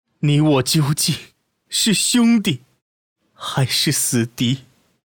声优采用成熟妩媚且富有磁性的音色，生动地展现了貂蝉不仅貌美如花，更是智勇双全的形象。
标签：游戏  年轻   女人   御姐